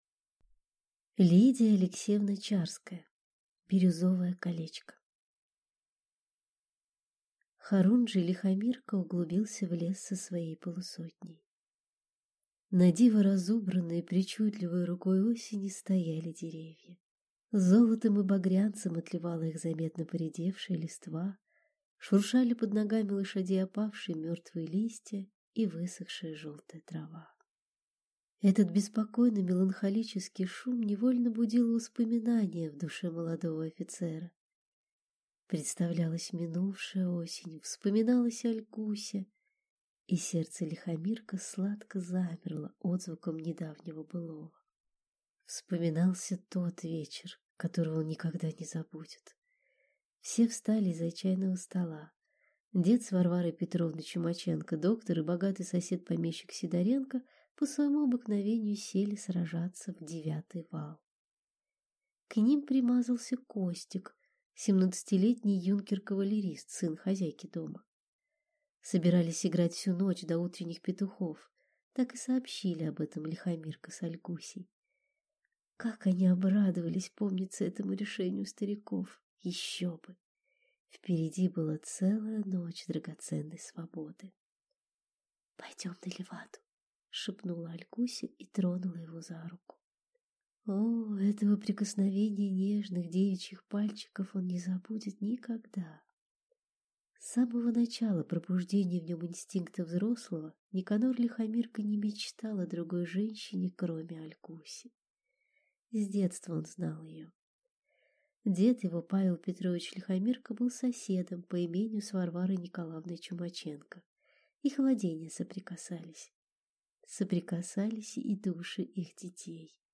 Аудиокнига Бирюзовое колечко | Библиотека аудиокниг